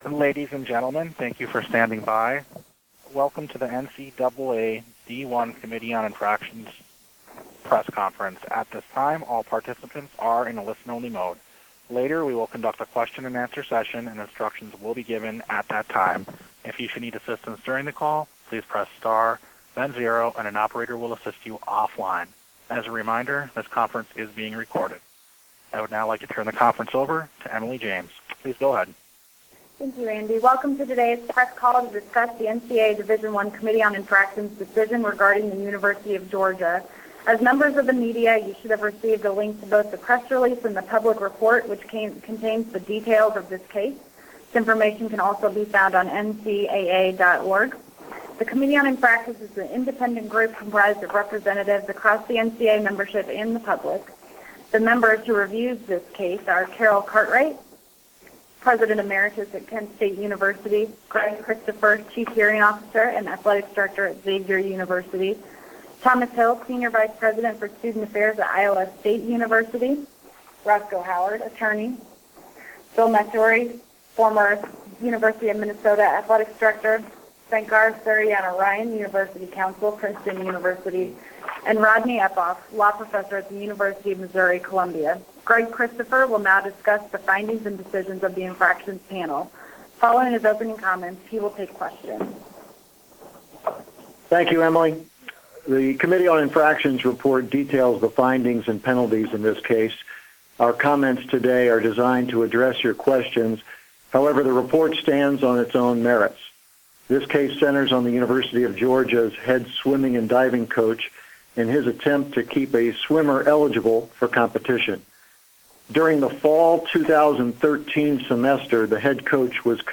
Media Teleconference Recording - NCAA Division I Committee on Infractrions - University of Georgia